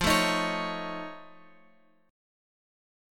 F-Augmented 7th-F-x,8,7,8,x,9.m4a